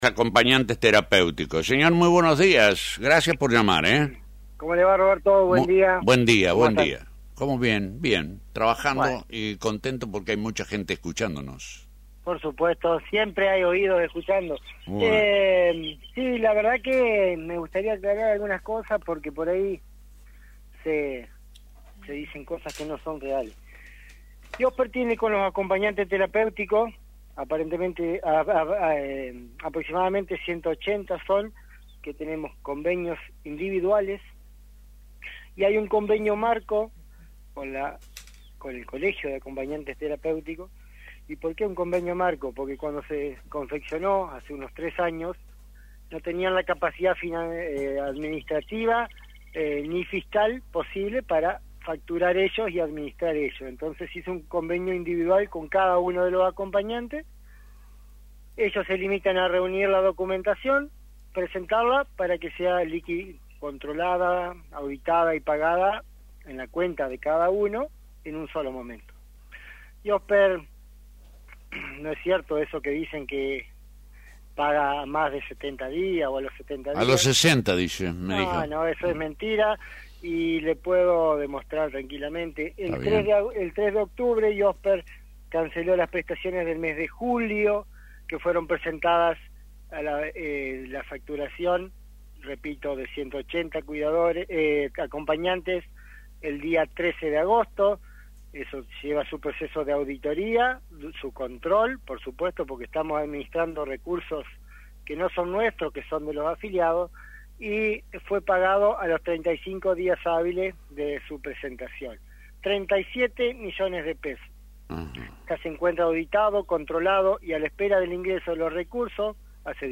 En diálogo con AM980